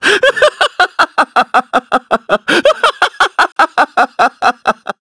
Shakmeh-Vox_Happy5_kr.wav